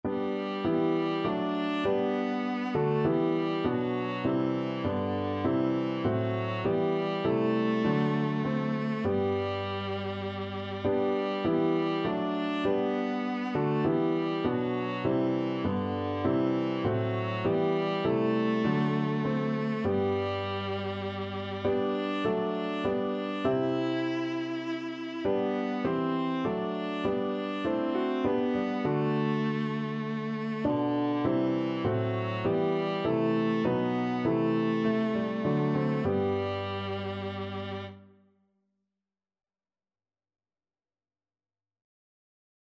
Christian
3/4 (View more 3/4 Music)
Classical (View more Classical Viola Music)